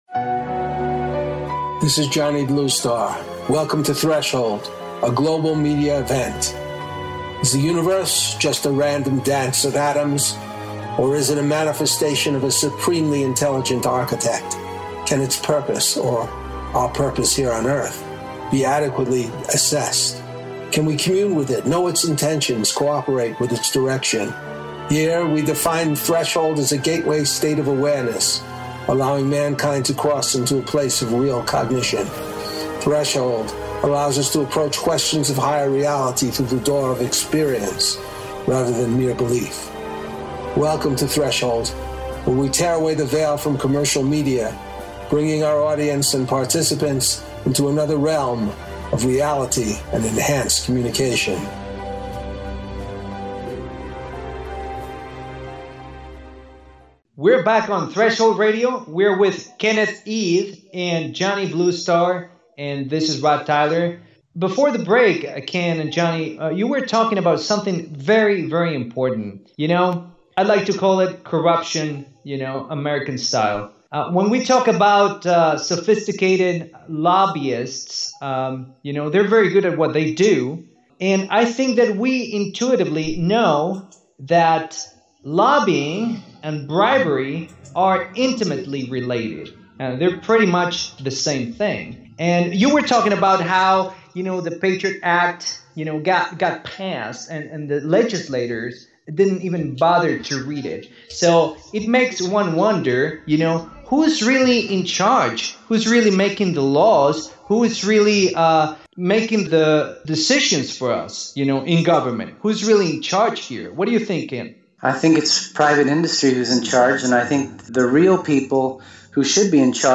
Talk Show Episode
In this show, the round table discusses the erosion of civil liberties in the United States, including the attacks on privacy, which has been extended to the current current administration.